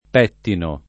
pettino [ p $ ttino ]